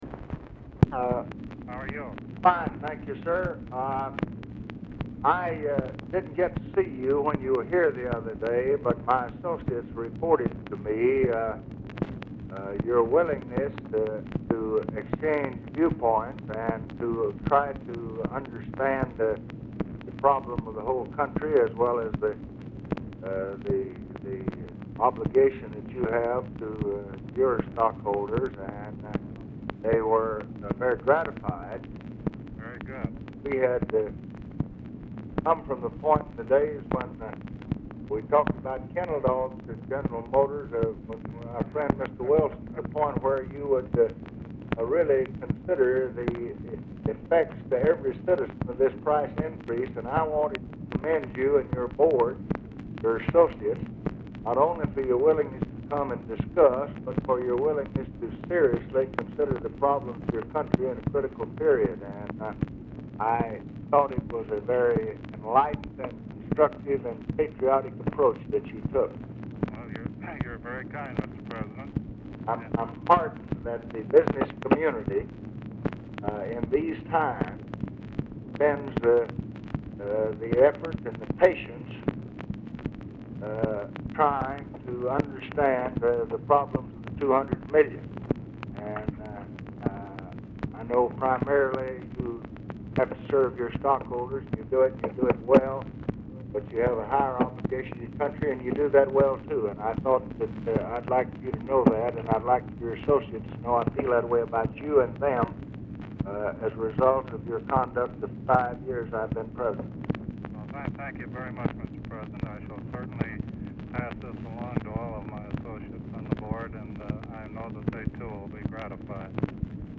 Telephone conversation # 13421, sound recording, LBJ and JAMES ROCHE, 9/26/1968, 4:40PM | Discover LBJ
RECORDING STARTS AFTER CONVERSATION HAS BEGUN
Format Dictation belt